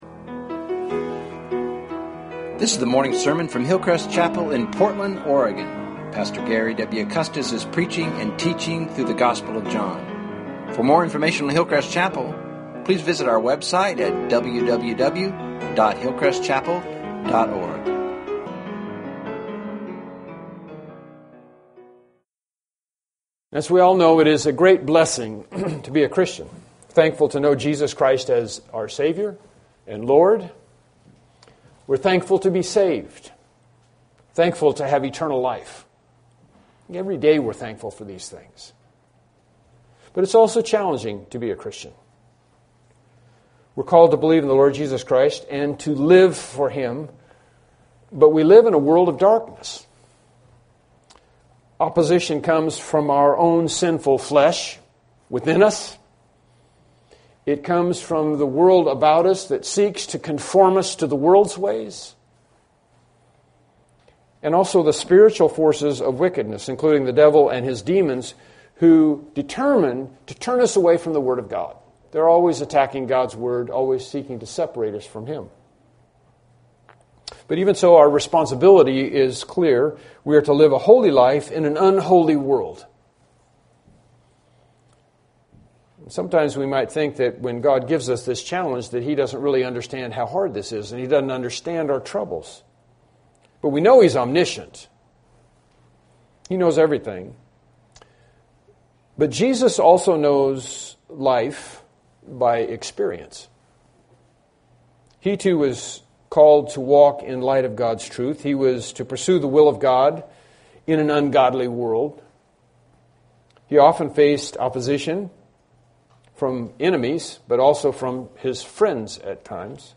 Bible Text: John 11:7-9 | Preacher